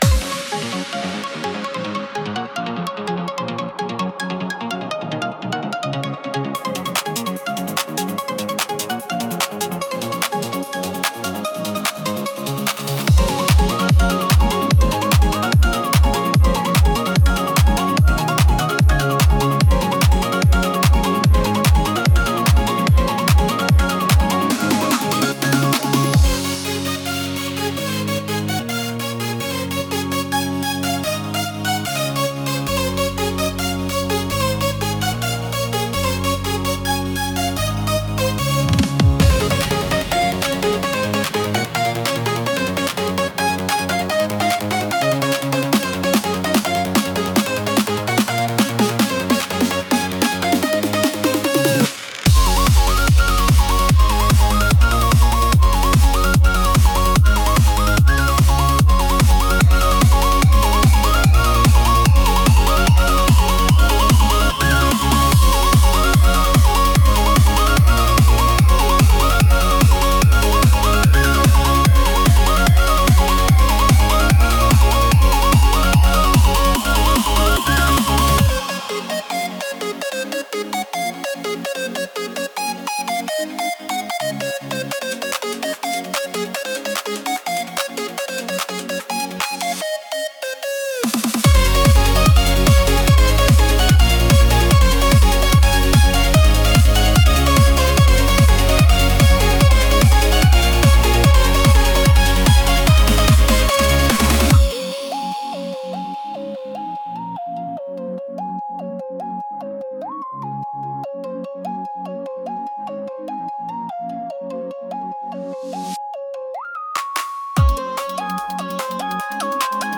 [Instrumental]
老若男女問わず、ちょっぴりノリノリになれる そんなBGMです